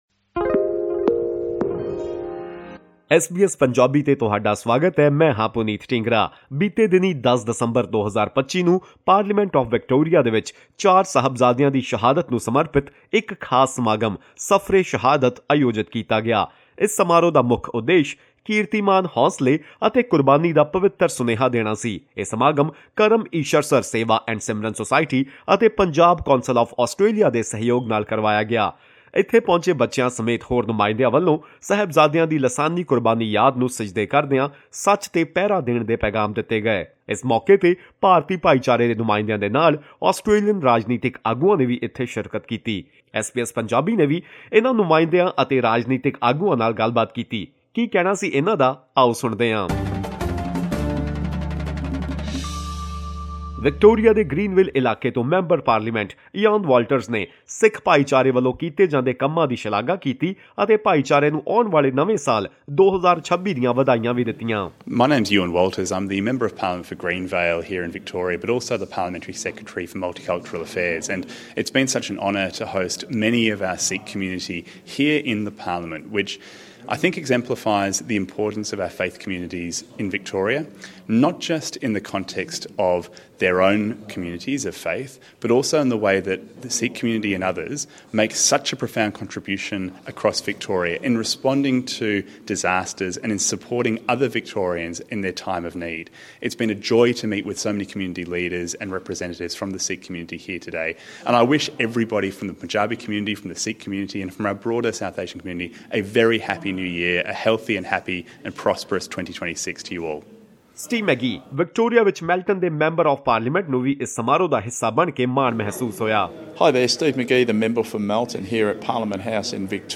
On 10 December 2025, a special programme titled “Safar-e-Shahadat” was organised at the Victorian Parliament to commemorate the martyrdom of the four Sahibzade. The event aimed to convey the sacred message of valour, resilience and sacrifice to the younger generation.
To hear what the attending representatives and political leaders had to say, tune in to this podcast.